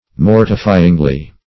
Mortifyingly \Mor"ti*fy`ing*ly\
mortifyingly.mp3